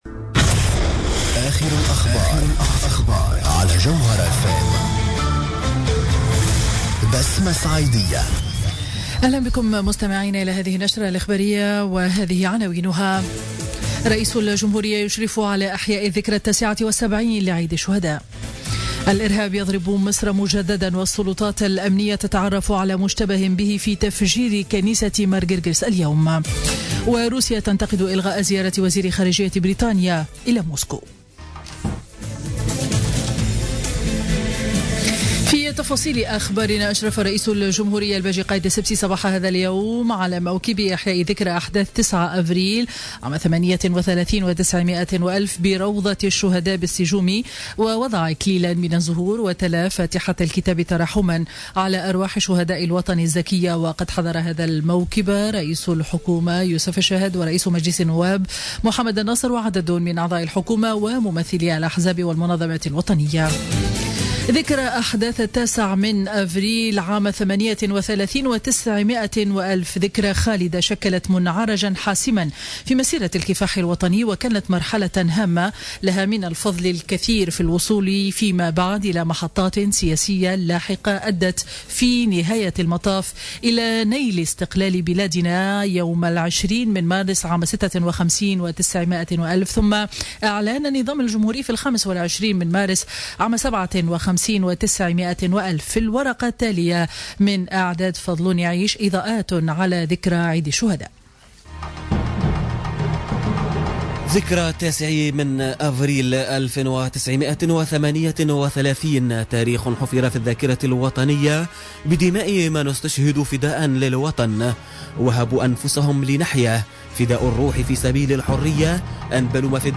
نشرة أخبار منتصف النهار ليوم الأحد 9 أفريل 2017